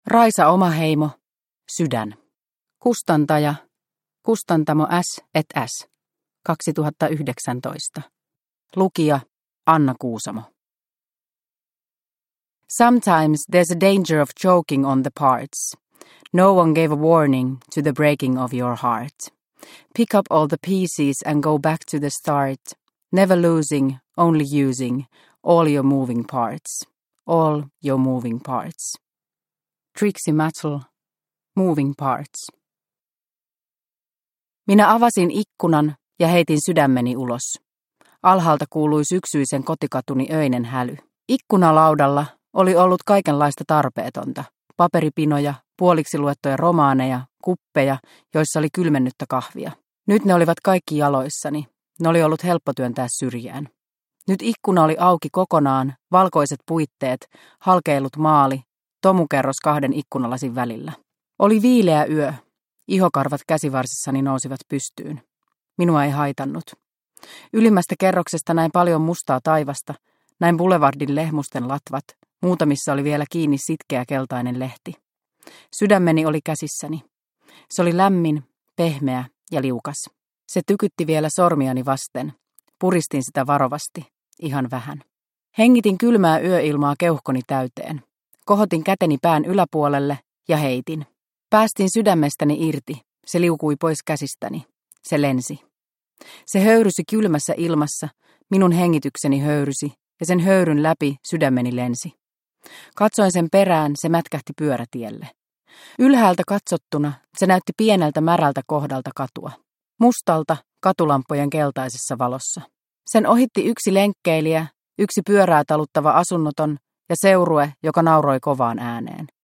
Sydän – Ljudbok – Laddas ner